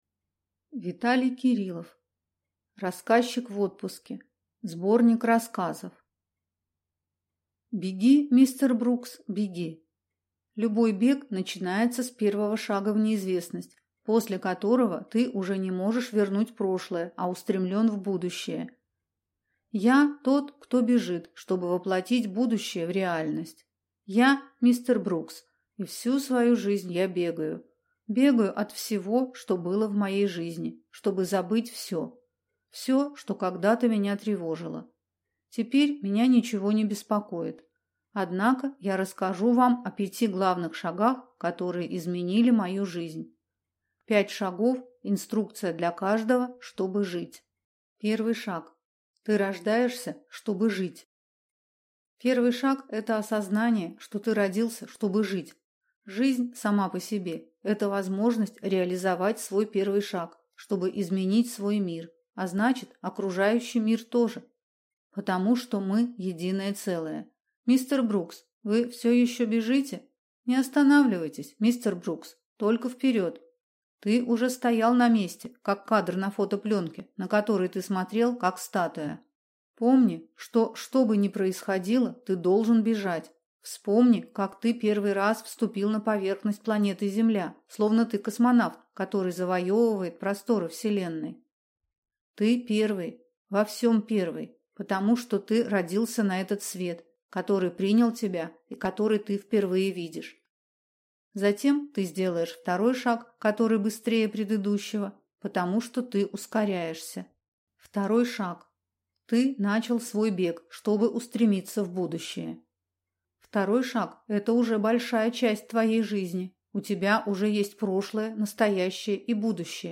Аудиокнига Рассказчик в отпуске. Сборник рассказов | Библиотека аудиокниг